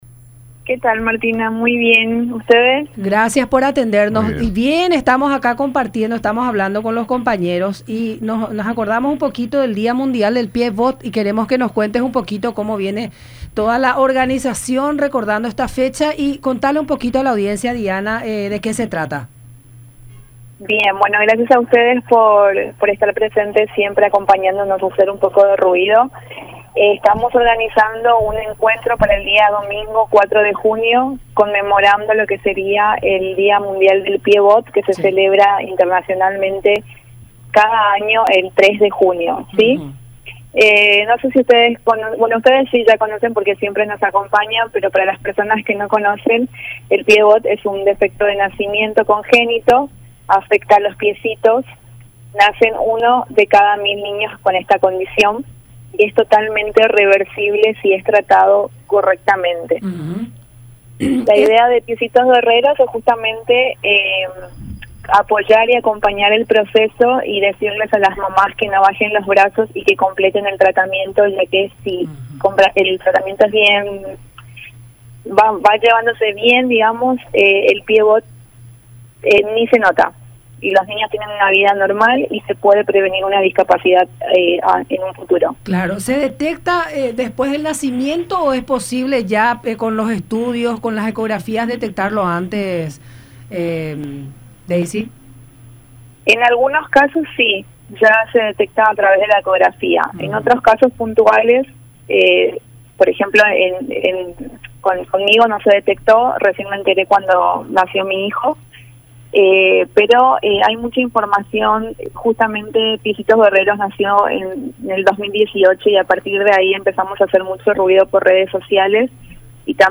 en diálogo con La Mañana De Unión por Unión TV y radio La Unión.